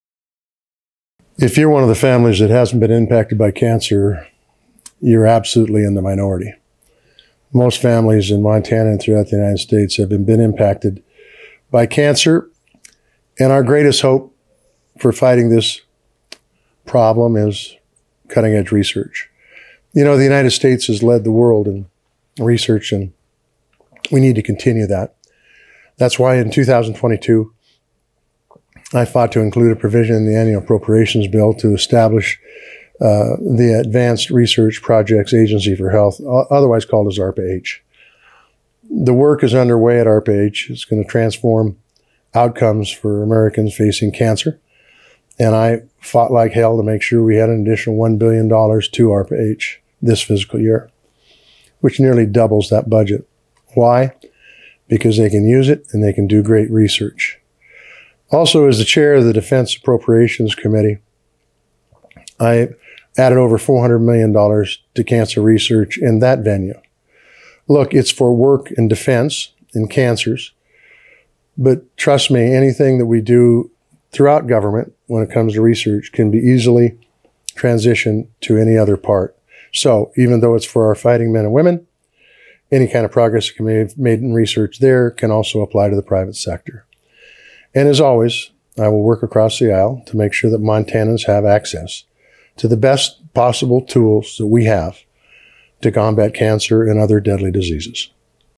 The candidates' responses were aired on Voices of Montana, October 9-11, 2024.